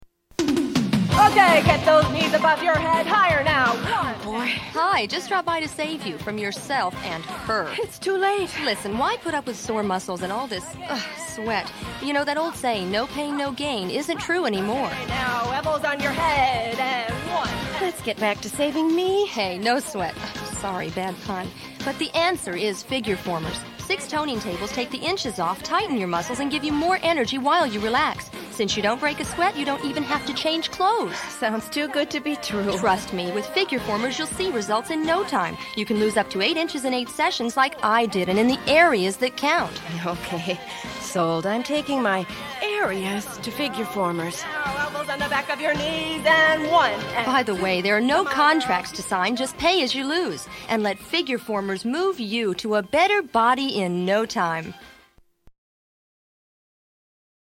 *Expanded Voice Demo available upon request